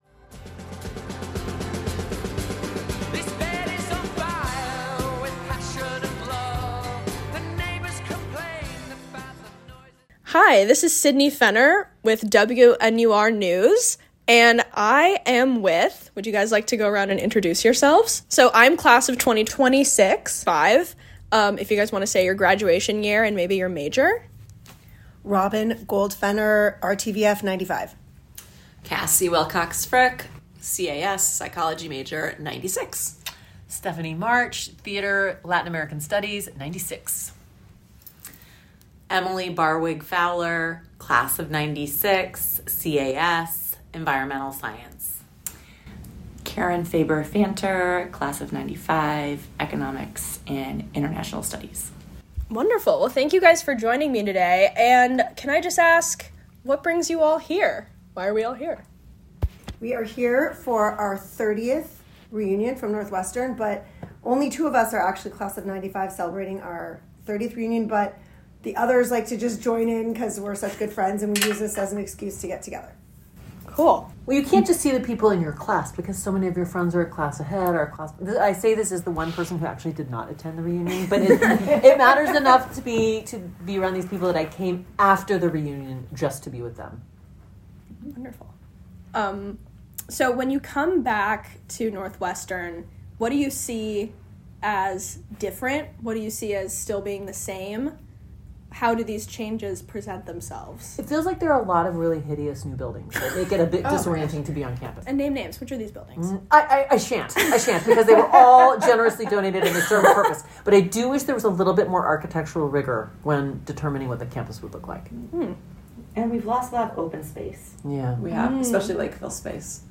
2025 Reunion Weekend Roundtable